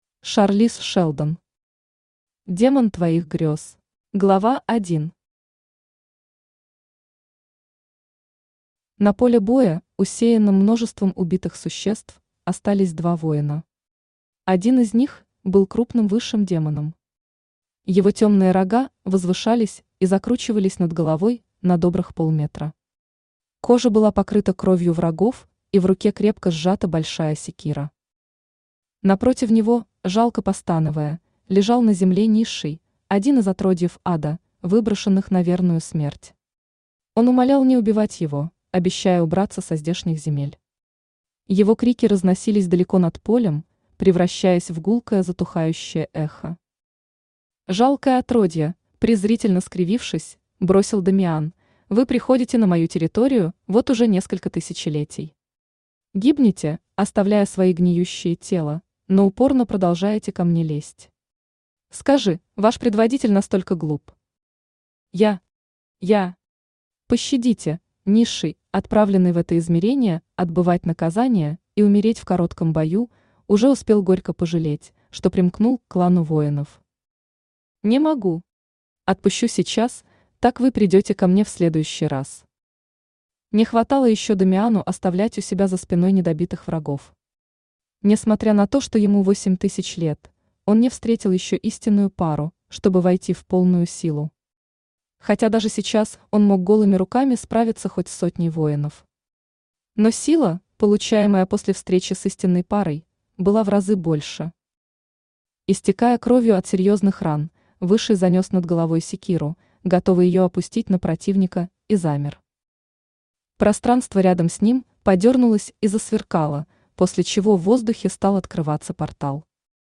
Aудиокнига Демон твоих грез Автор Шарлиз Шелдон Читает аудиокнигу Авточтец ЛитРес.